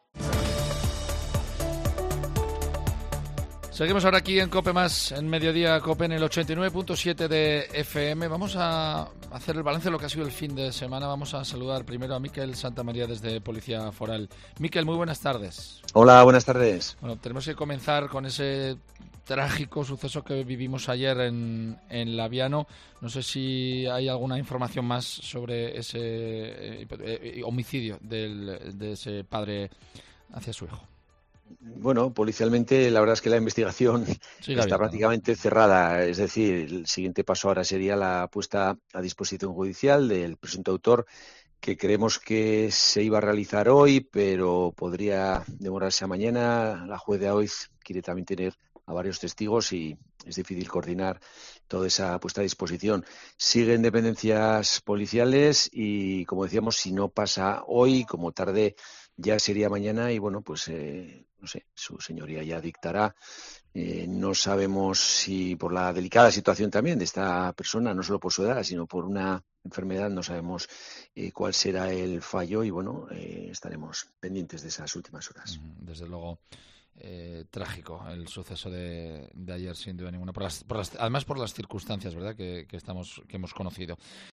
Policía Foral explica la detención de un hombre como presunto autor de un homicidio en Labiano